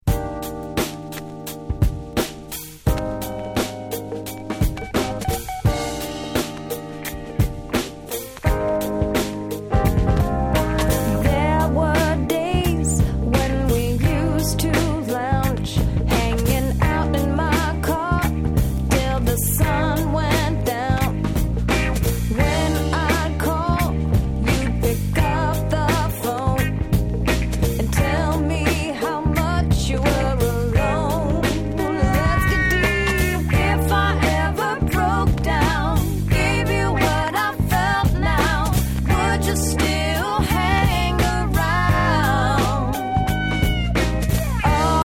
Album Version